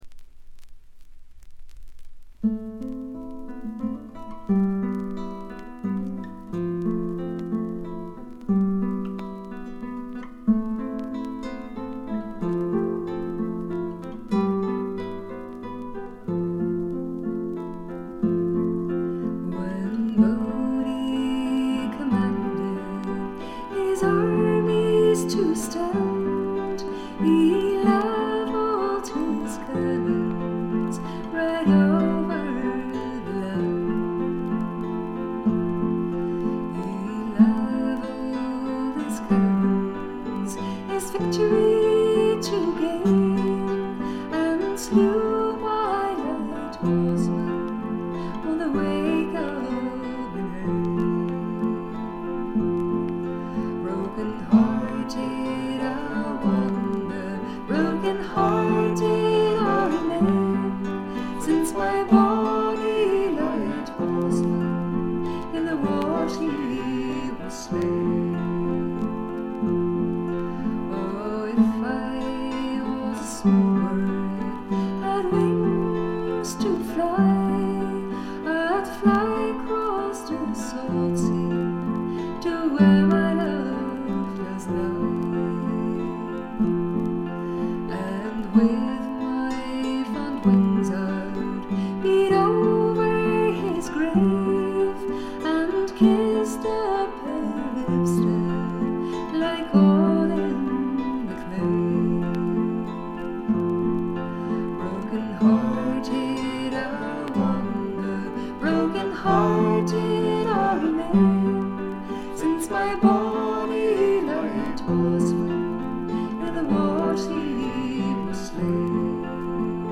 バックグラウンドノイズ、チリプチ多め大きめ。
オランダのトラッド・フォーク・グループ
試聴曲は現品からの取り込み音源です。
※A1-A2連続です。A1終了間際から周回ノイズ。
Guitar, Vocals
Violin, Flute, Mandolin, Whistle, Keyboards, Vocals